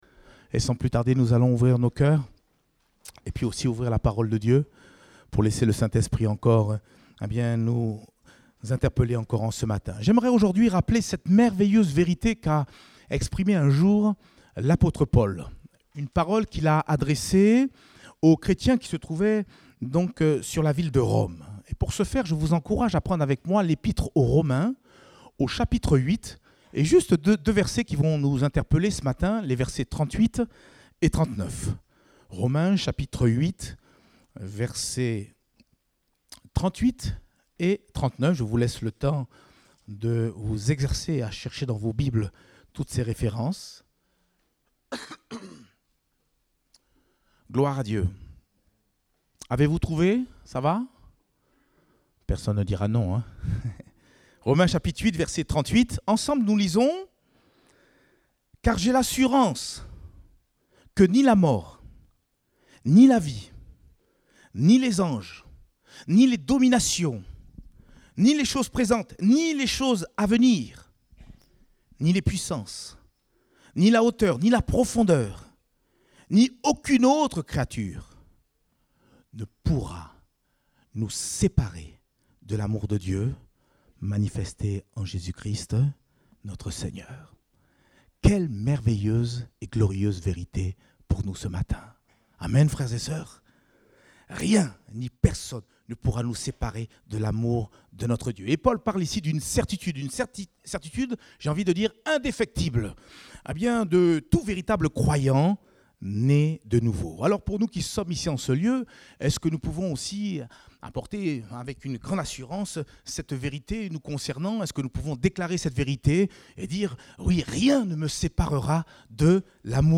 Date : 27 septembre 2020 (Culte Dominical)